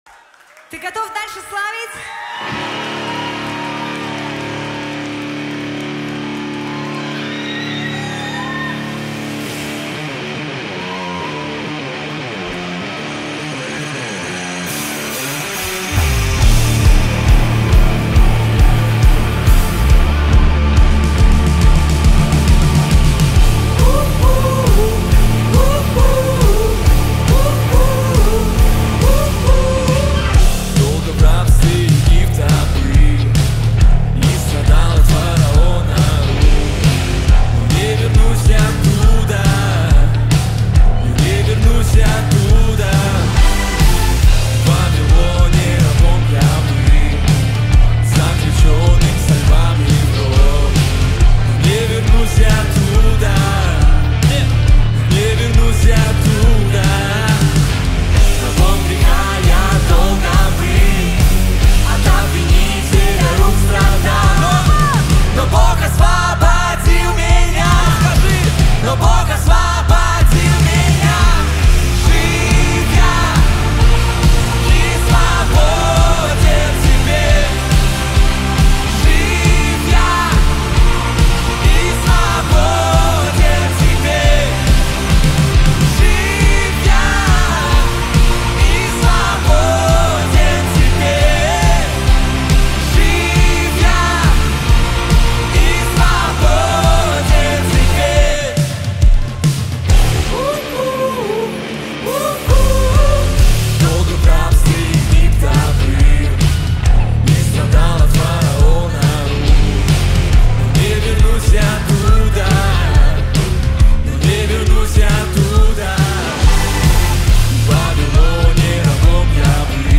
1192 просмотра 1029 прослушиваний 71 скачиваний BPM: 136